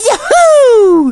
One of two voice clips from Mario in Super Mario Galaxy when he back flips.
SMG_Mario_Yahoo_(backflip).wav